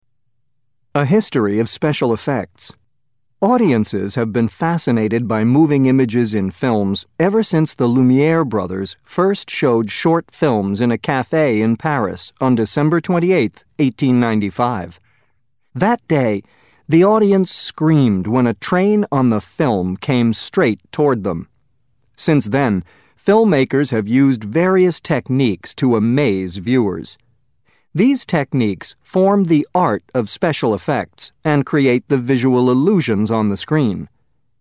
قطعه قرائيه جاهزه للصف الثالث متوسط ف2 الوحده الخامسه mp3